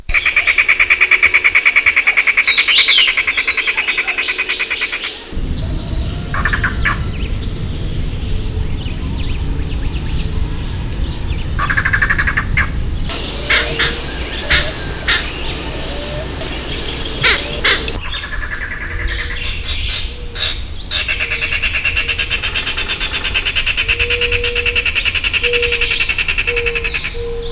"Pájaro Bobo Mayor"
Coccyzus vieilloti
pajaro-bobo-mayor.wav